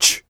Index of /90_sSampleCDs/ILIO - Vocal Planet VOL-3 - Jazz & FX/Partition G/8 FEM PERC 2